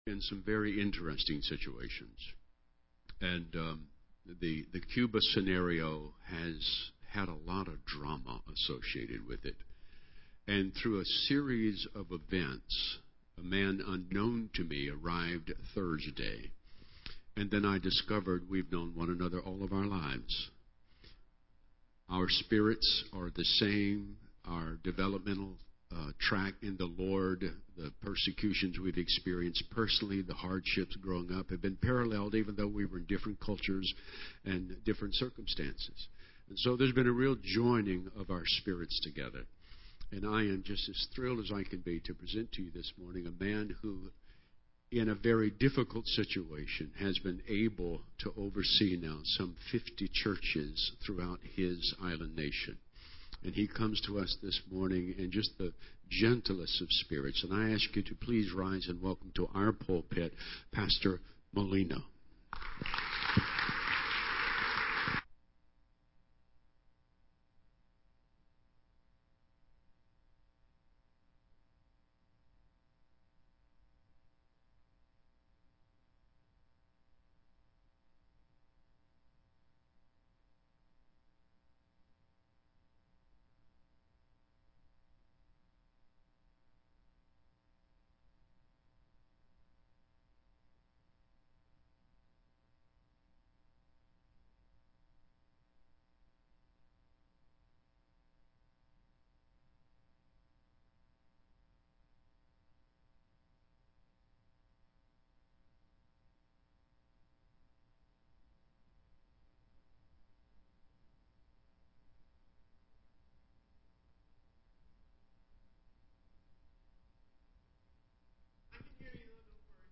Sunday morning sermon
Presented by Visiting Pastor on 03/15/2015File size: 6.8 Mb